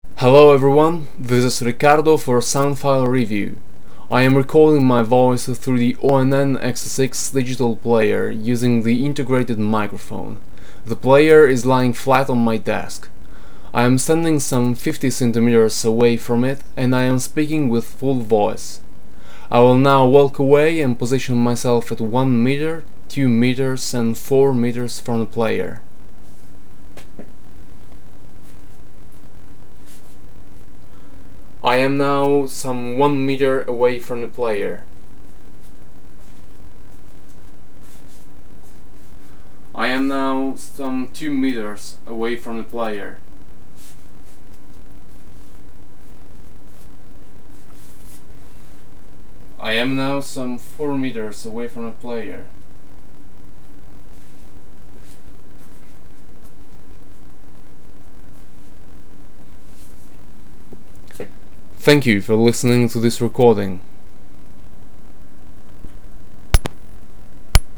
sample recording (untouched .wav file produced by the player), there is a vast amount of background noise even though the recording environment was silent. There is an audible double click at the end of the recording when I unlock the screen and stop the recording.